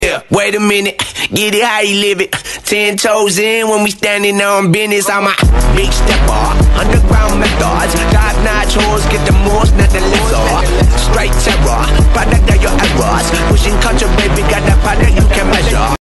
electrovoice XLINE low 15incX2 sound effects free download
electrovoice XLINE low 15incX2 mid 8incX2 high 3incX3